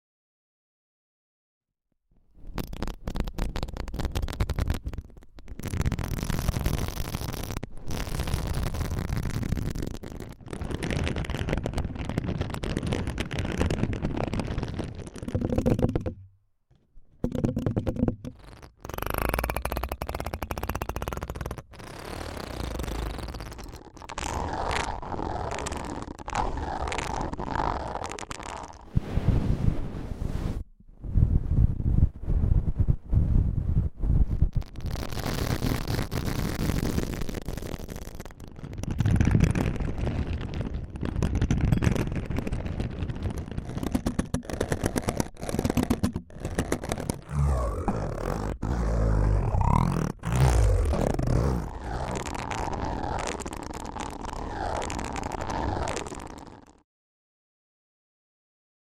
Brain Massage ASMR! Watch The Sound Effects Free Download